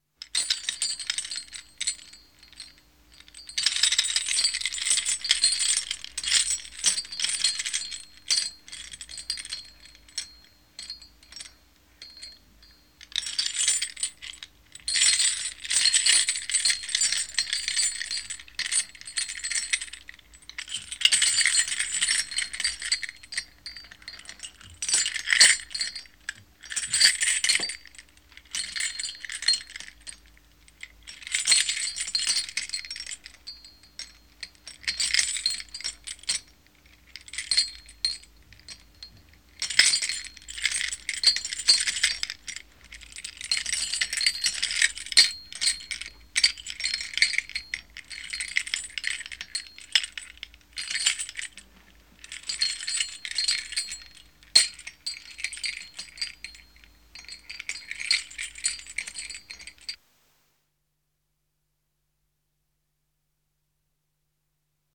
chains_shaken_1969_89_e_reprocessed_
Category: Sound FX   Right: Personal